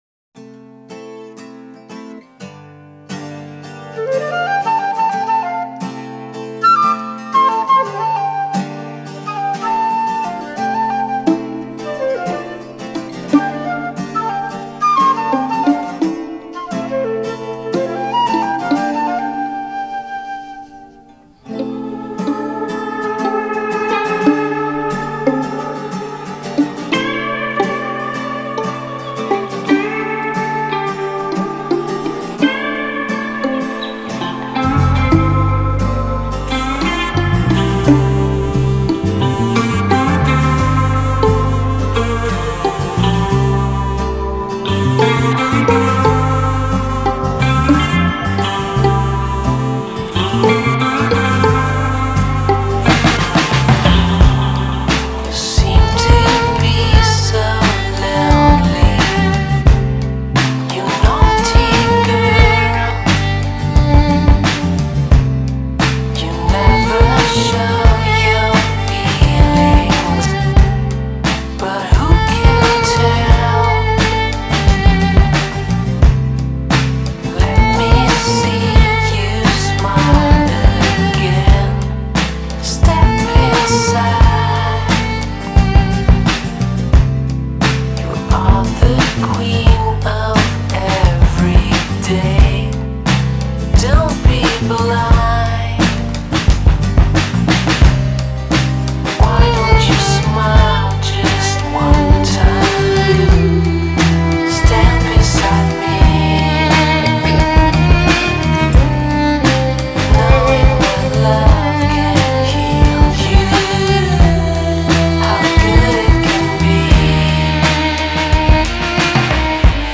STRING-LOUNGE DEMO
Live-Violine